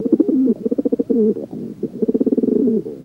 Dove Pigeon Coo